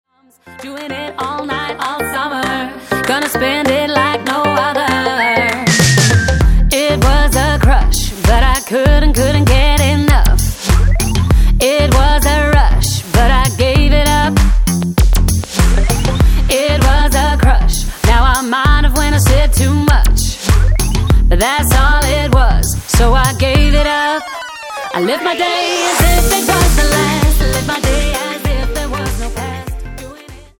--> MP3 Demo abspielen...
Tonart:Eb Multifile (kein Sofortdownload.